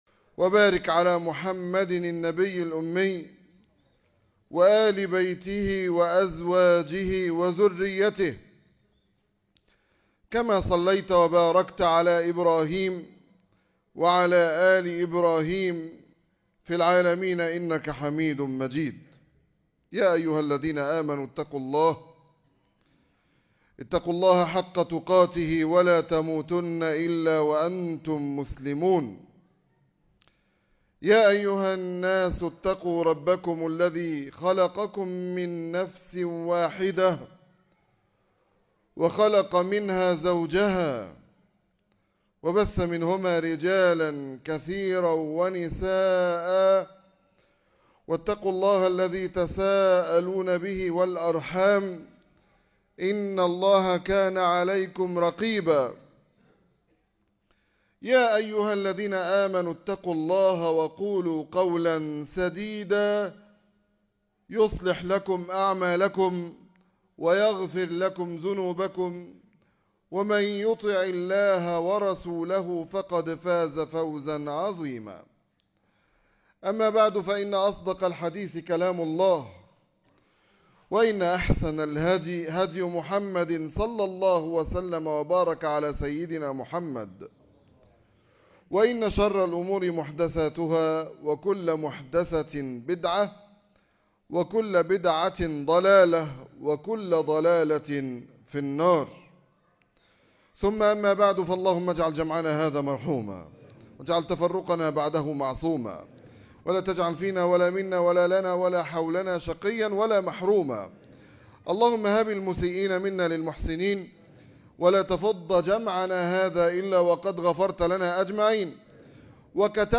حج الفقراء _ خطبة مهمة لمن يريد الحج